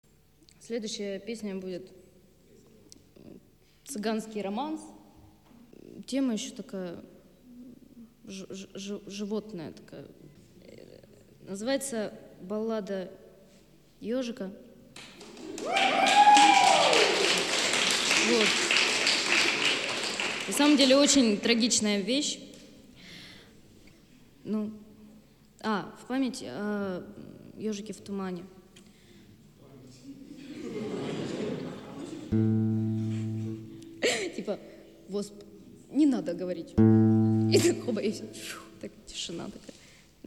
цыганский романс